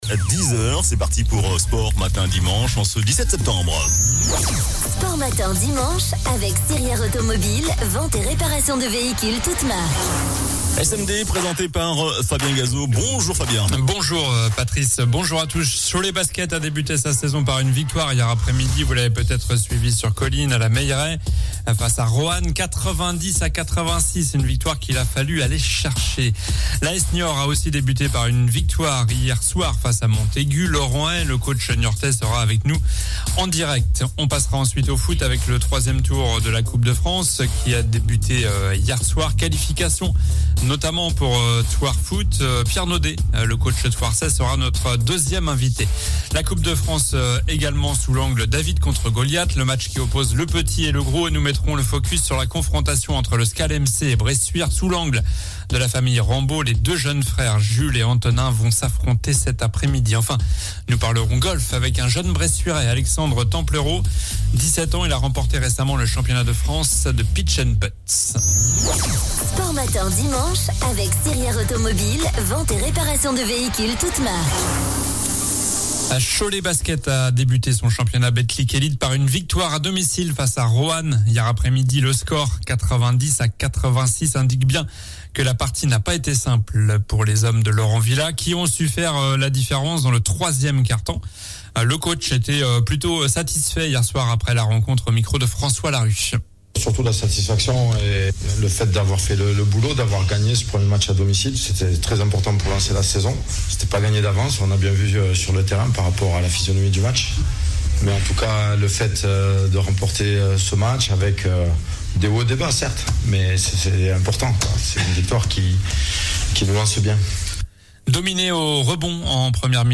sera avec nous en direct